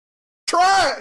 Trash Basketball Sound Effect - Bouton d'effet sonore